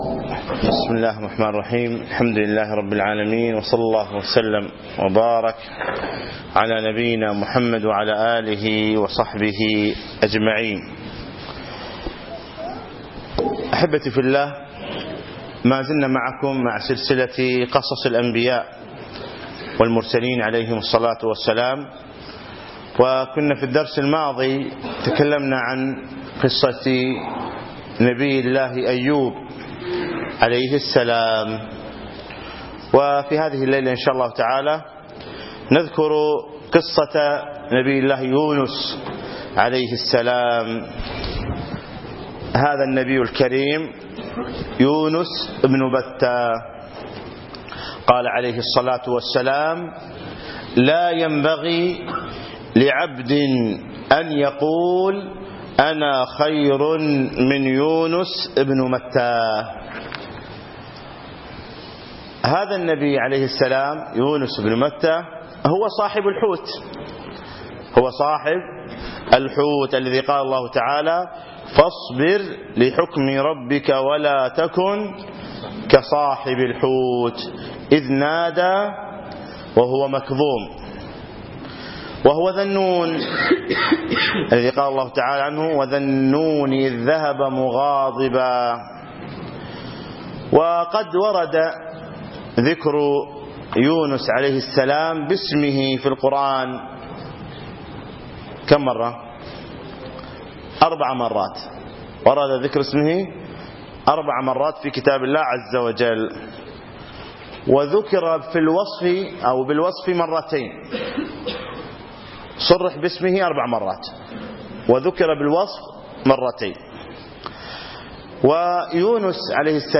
أقيمت المحاضرة يوم الجمعة 6 3 2015 في مسجد عطارد بن حاجب منطقة الفروانية